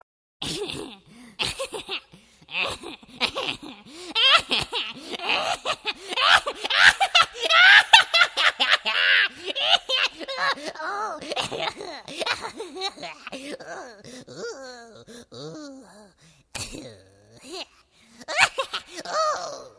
Категория: Различные звуковые реалтоны